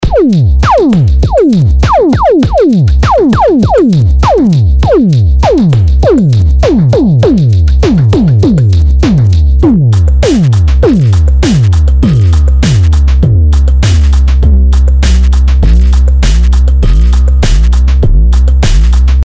Hier habe ich – ausgehend vom Preset „At the Butchers 3“ – den Parameter „Tone“ des Distortion-Moduls, den Cutoff des Filters und die Start-Frequenz des Sinus-Oszillators nach MIDI-Learn per Controller moduliert.